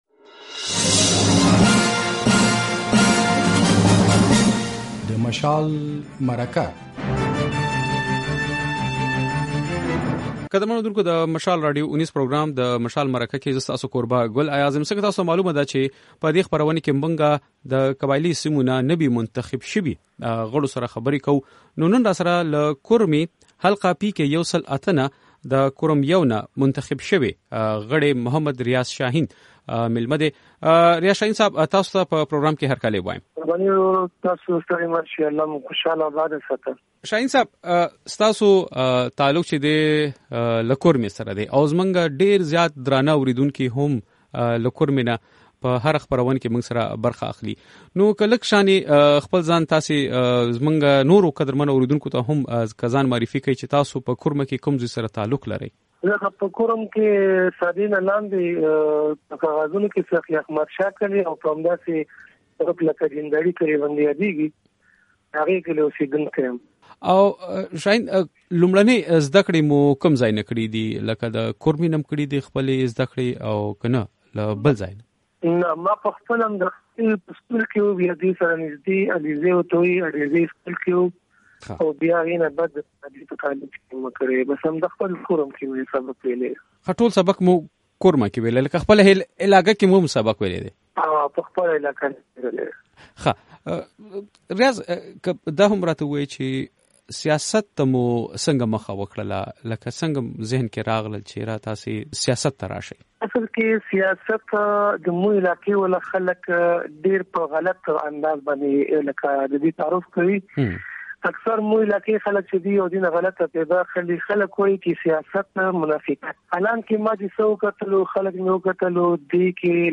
له ریاض شاهین سره د مشال مرکه
دا اوونۍ په مشال مرکه کې له کورمې ضلعې نه د خیبر پښتونخوا اسمبلۍ له غړي ریاض شاهین سره غږېدلي یو.